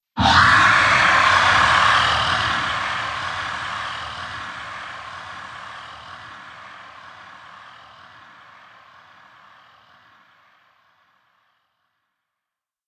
snake-roar-blast.ogg